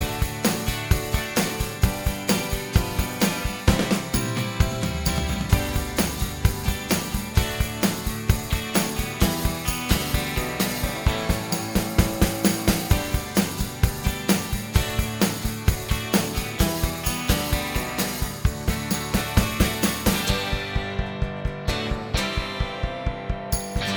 Minus Bass Pop (1970s) 3:34 Buy £1.50